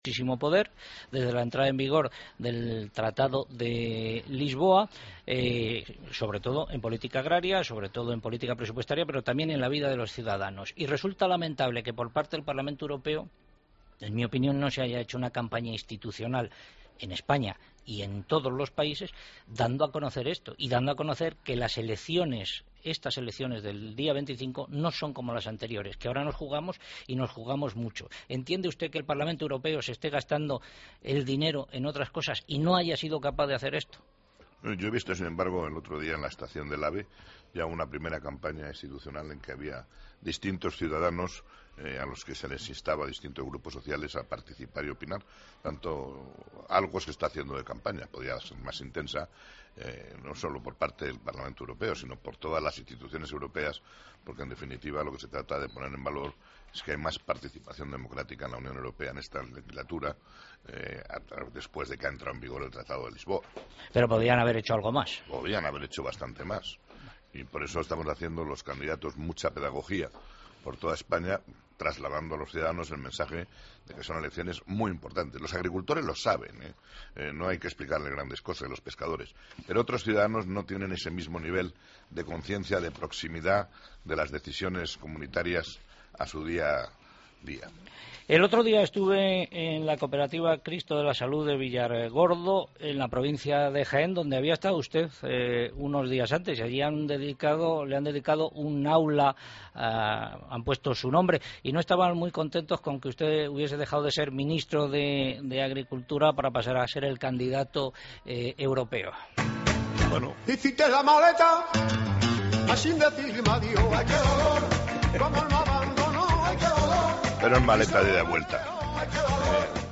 Entrevista a Arias Cañete en Agropopular